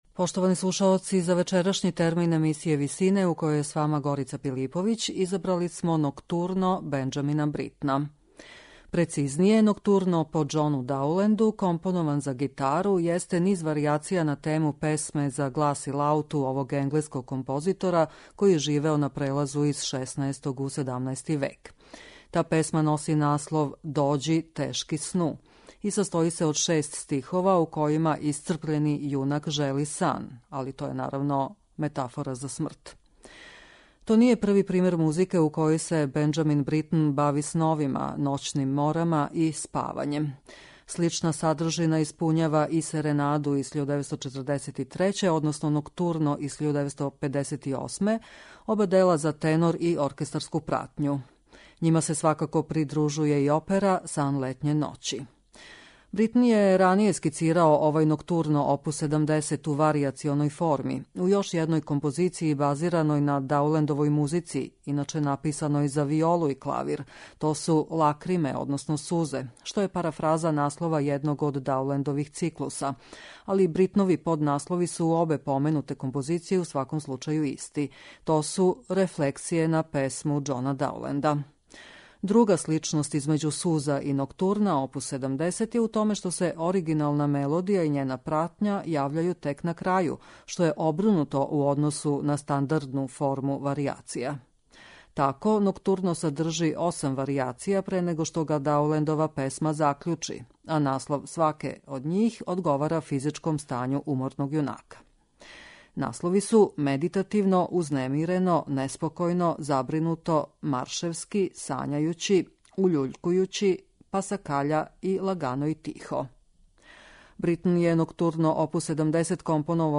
компонован за гитару